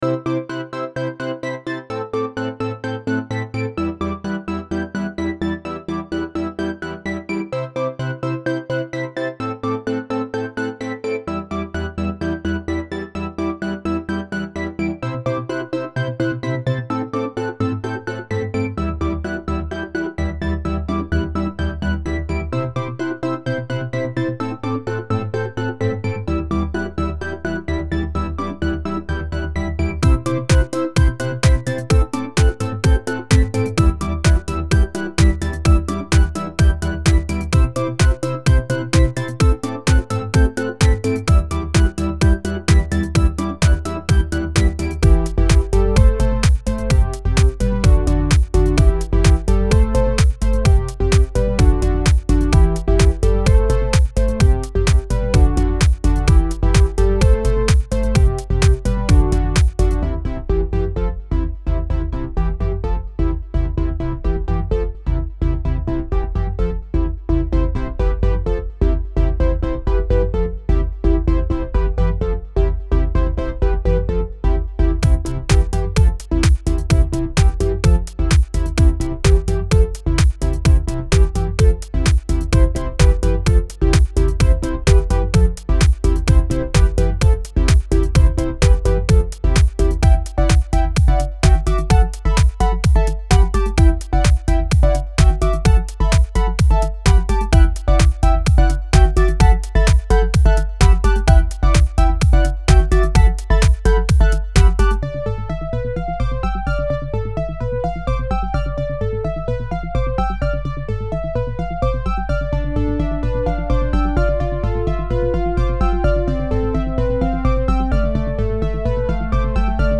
Home > Music > Electronic > Bright > Running > Chasing